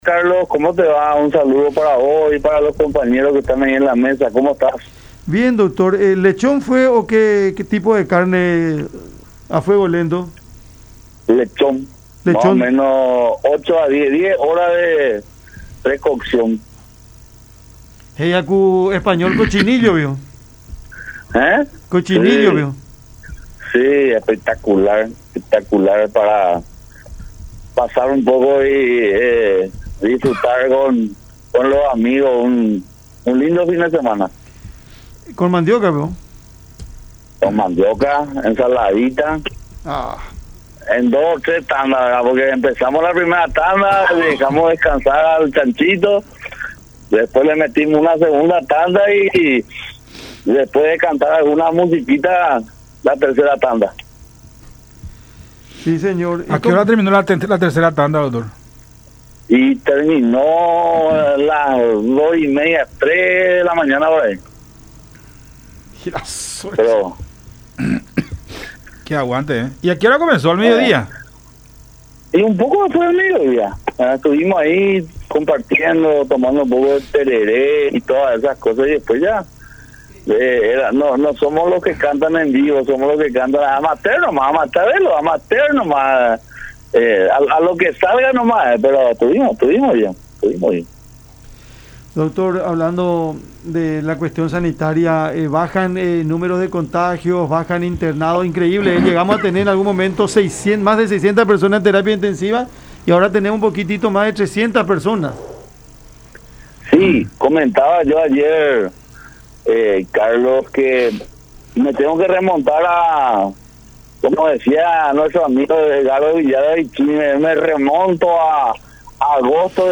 Por eso, los que no se vacunan son un peligro para los demás”, dijo Silva en diálogo con Cada Mañana por La Unión.